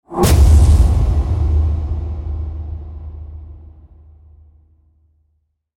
Cinematic-scary-sudden-hit-sound-effect.mp3